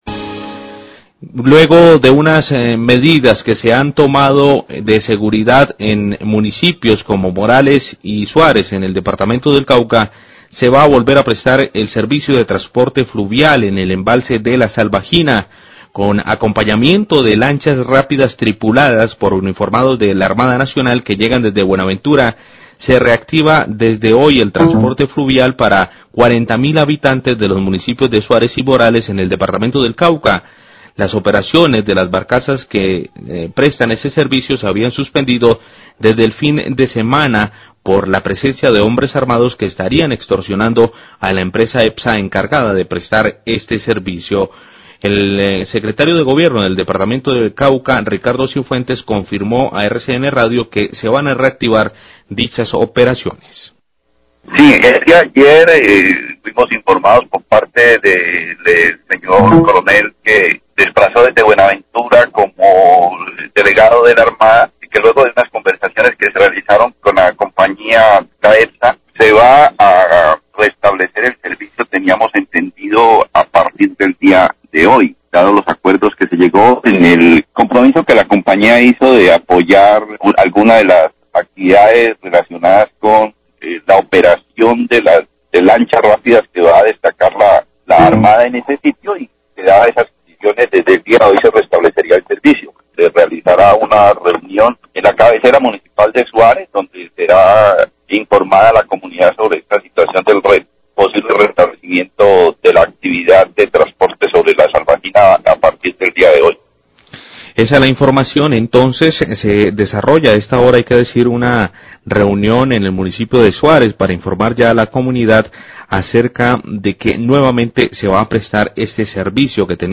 Radio
Declaraciones del Secretario de Gobierno del Cauca, Ricardo Cifuentes.